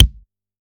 soft-hitnormal.mp3